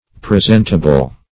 Presentable \Pre*sent"a*ble\, a. [Cf. F. pr['e]sentable.]